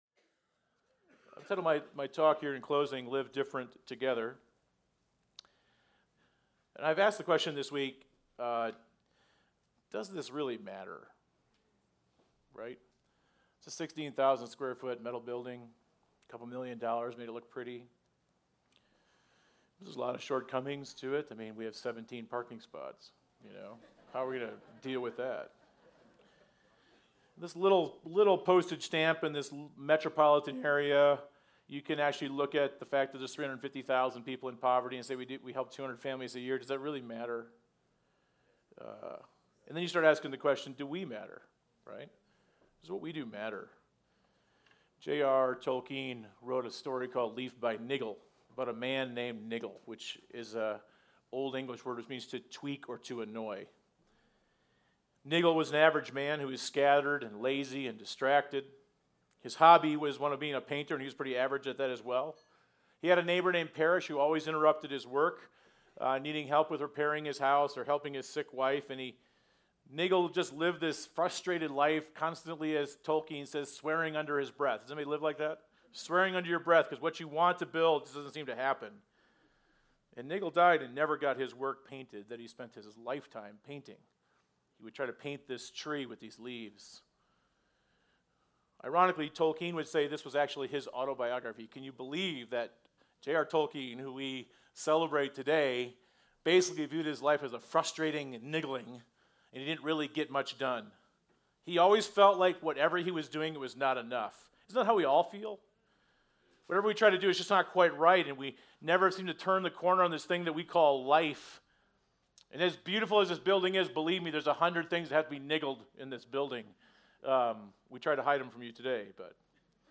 Message: “Live Different Together”